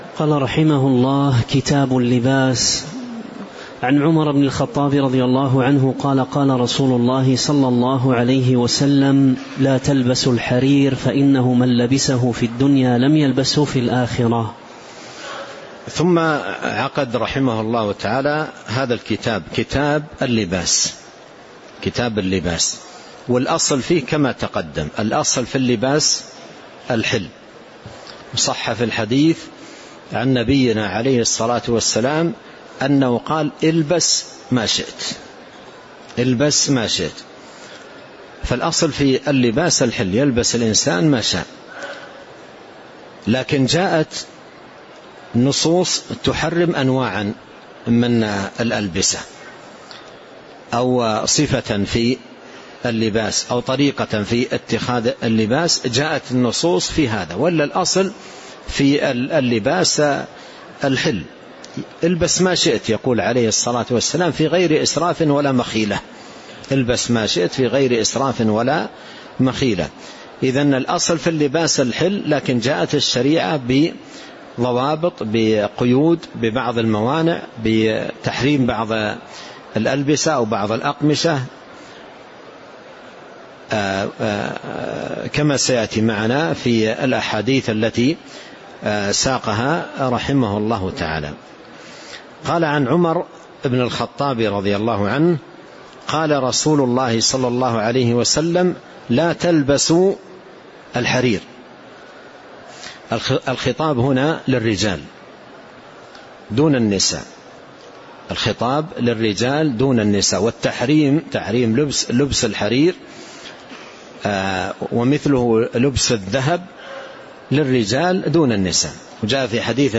تاريخ النشر ٢٧ شعبان ١٤٤٤ هـ المكان: المسجد النبوي الشيخ